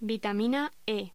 Locución: Vitamina E
voz